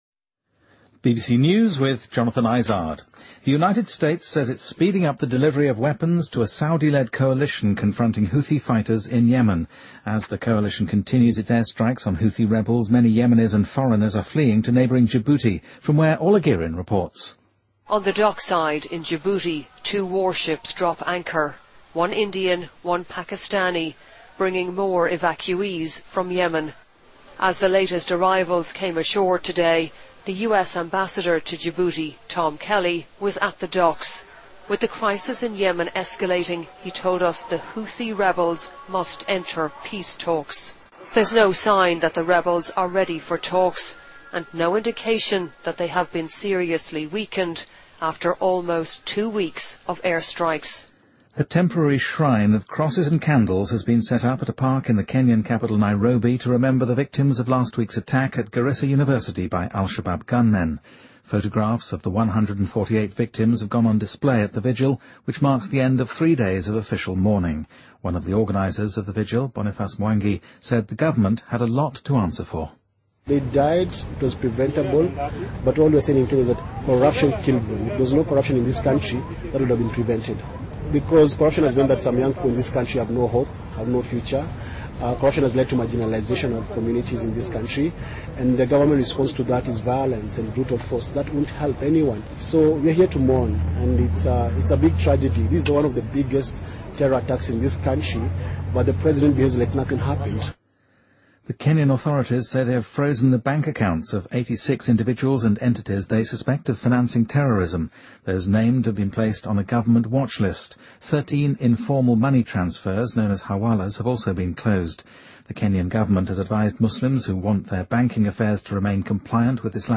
BBC news,肯尼亚冻结了86个涉嫌资助恐怖主义的银行账户